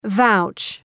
Προφορά
{vaʋtʃ}
vouch.mp3